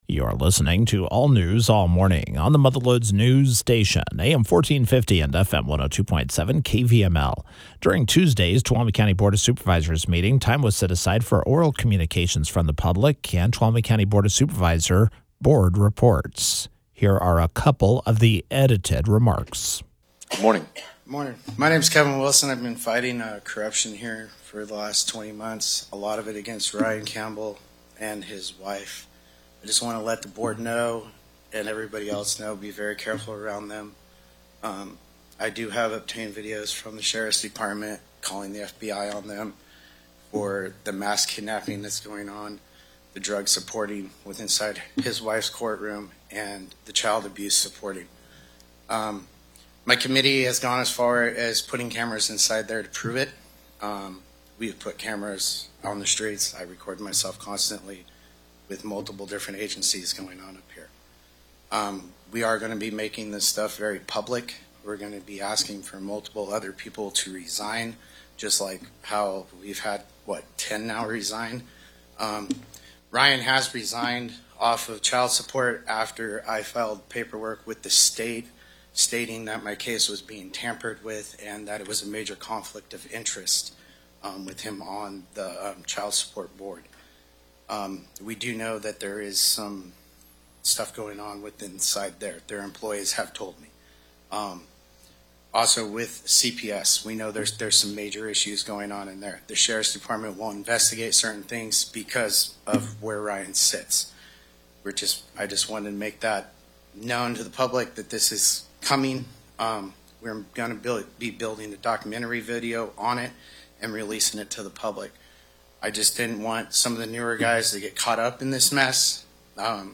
Campbell was Thursday’s KVML “Newsmaker of the Day”. Here is the edited audio: